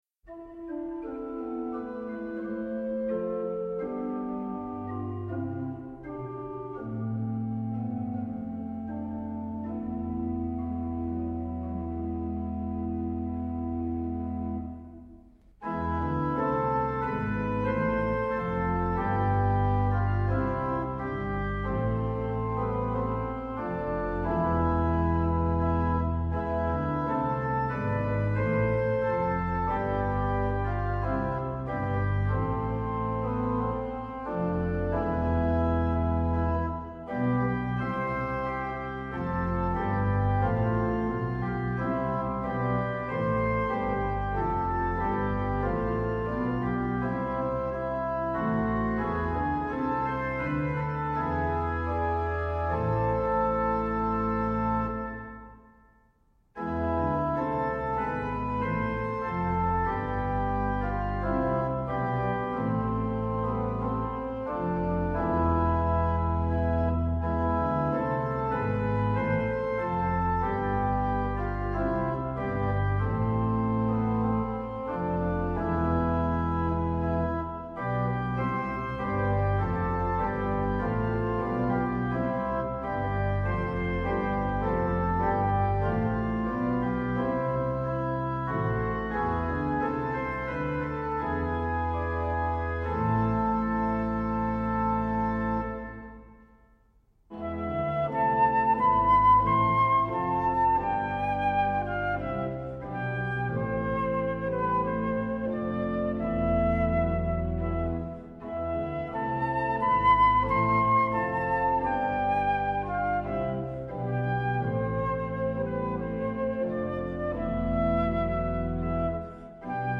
Hier sind die Orgelbegleitungen für die Choräle zum Mitsingen: